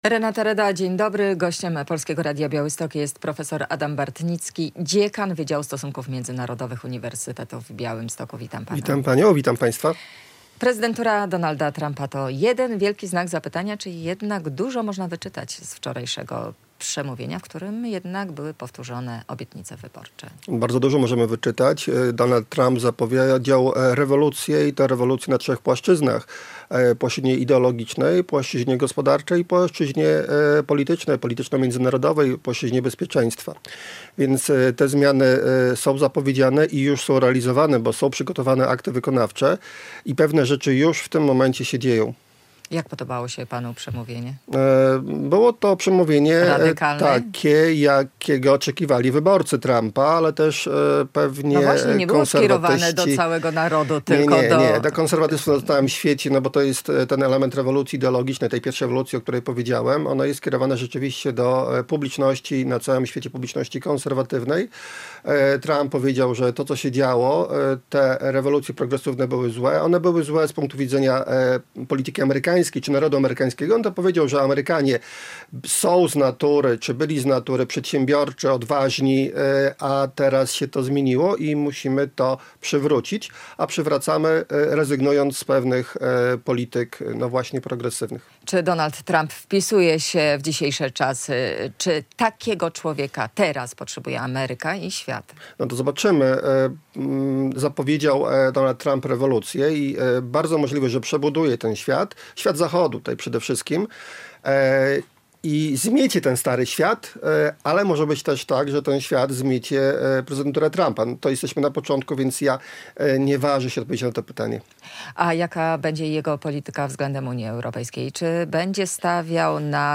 Czego możemy się spodziewać się po jego prezydenturze? O tym w Rozmowie Dnia.